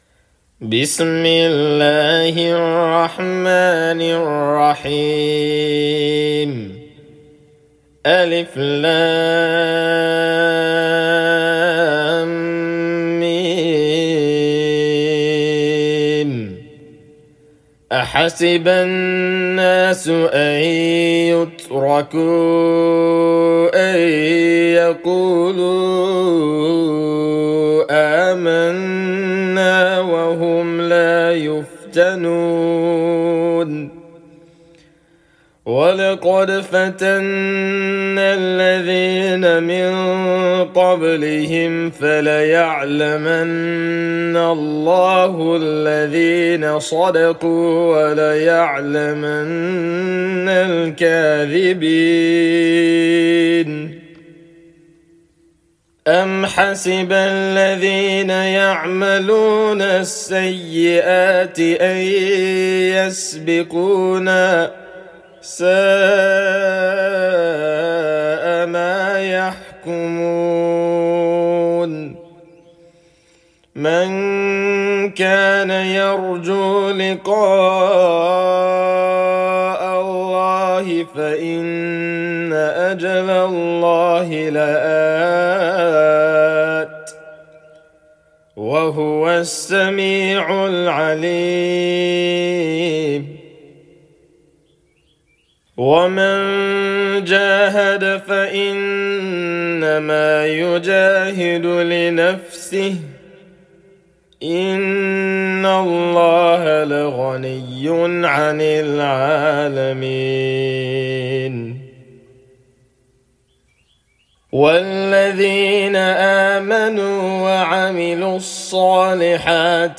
File:Chapter 29, Al-Ankabut (Murattal) - Recitation of the Holy Qur'an.mp3
Captions English Al-Ankabut, murattal, Hafs an Asim
Chapter_29,_Al-Ankabut_(Murattal)_-_Recitation_of_the_Holy_Qur'an.mp3